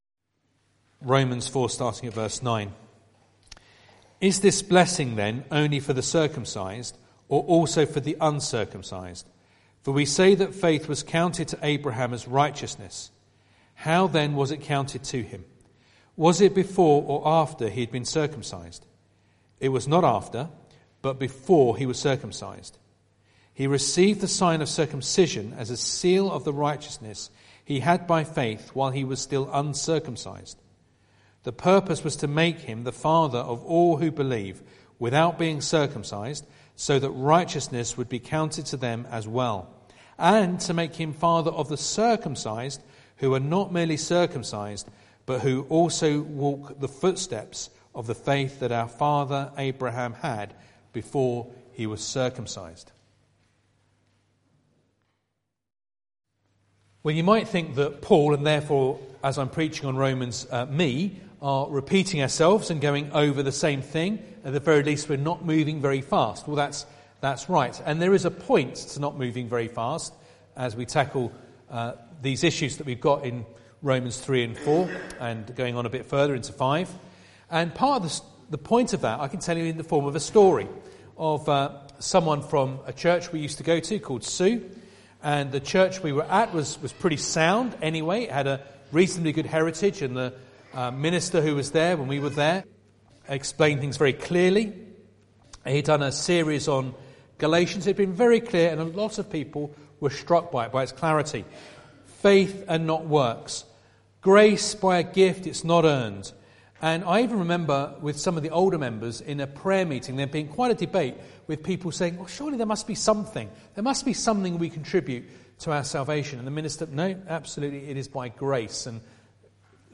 Romans 4:9-12 Service Type: Sunday Evening Bible Text